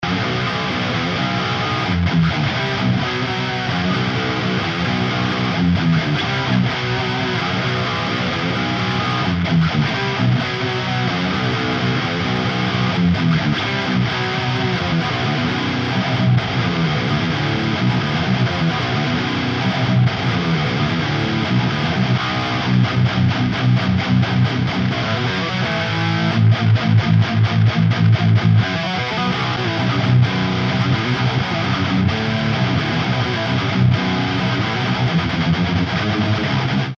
This loop pack is designed to deliver the raw power and intensity of hard rock and metal guitar, providing the perfect soundtrack for your high-energy productions.
72 Metal Guitar Loops: From bone-crushing riffs to soaring solos, these metal guitar loops cover a wide range of tempos and styles. Each loop is crafted to deliver the heavy, distorted tones and intricate melodies that define hard rock and metal music.